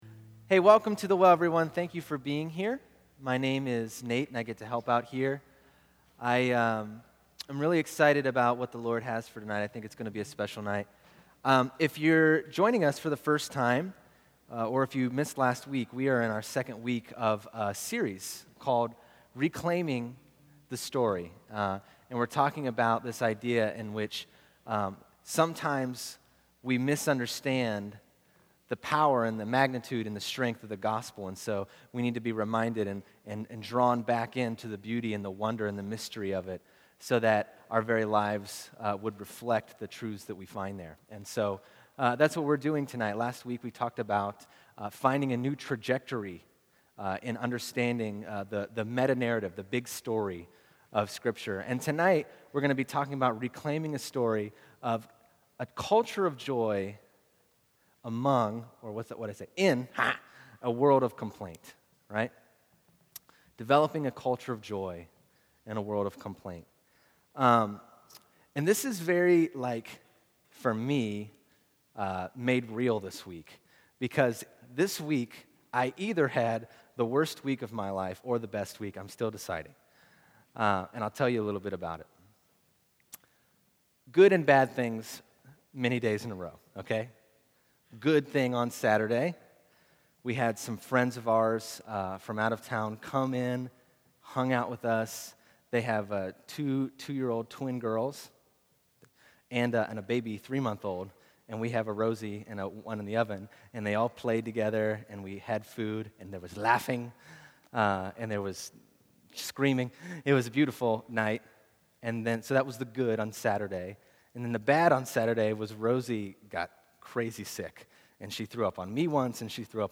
Sermons - The Well